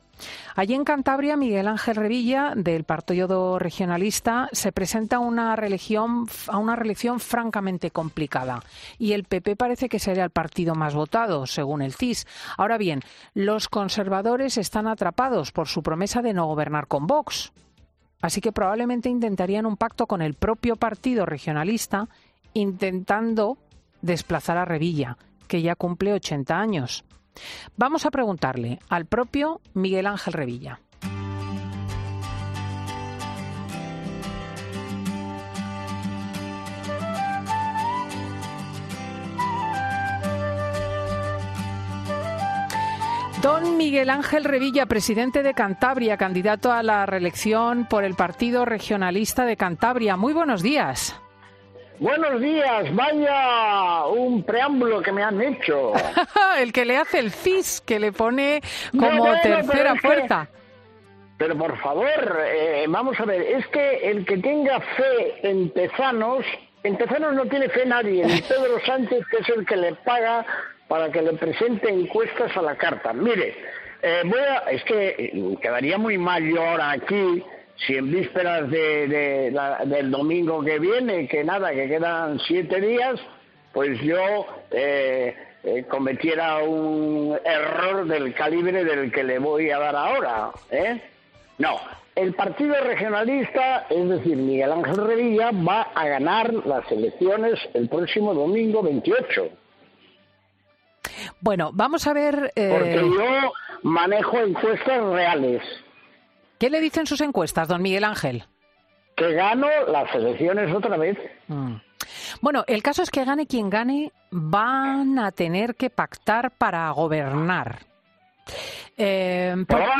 El presidente de Cantabria y candidato a renovar su puesto por el PRC, ha pasado por los micrófonos de 'Fin de Semana' antes de las elecciones del...